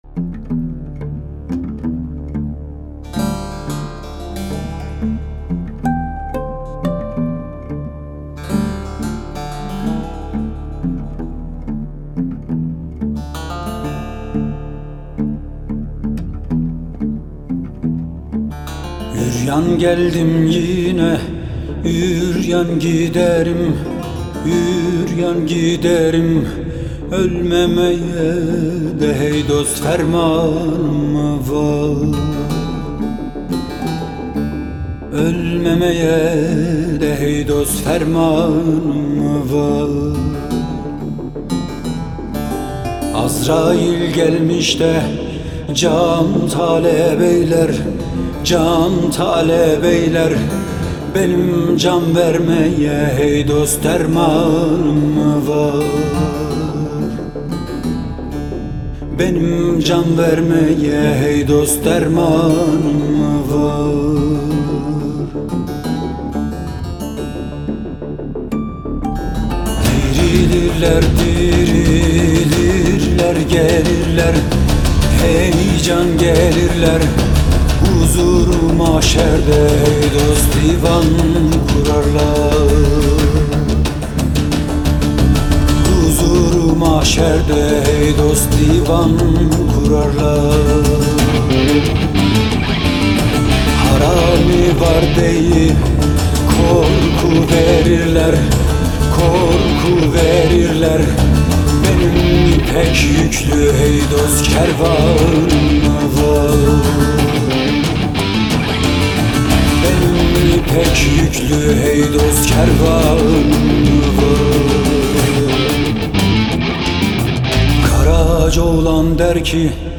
duygusal hüzünlü heyecan şarkı.